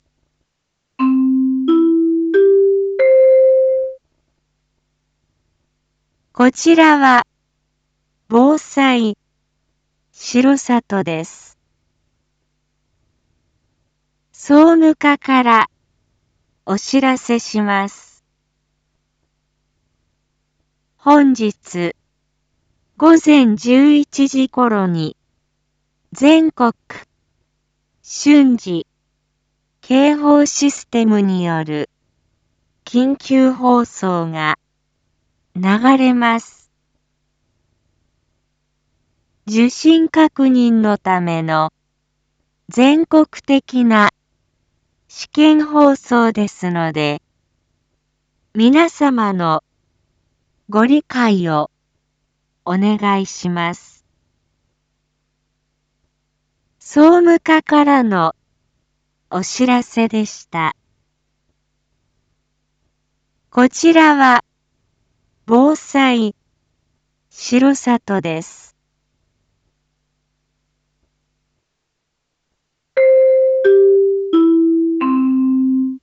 Back Home 一般放送情報 音声放送 再生 一般放送情報 登録日時：2024-02-08 07:01:17 タイトル：全国瞬時警報システムの訓練放送について インフォメーション：こちらは防災しろさとです。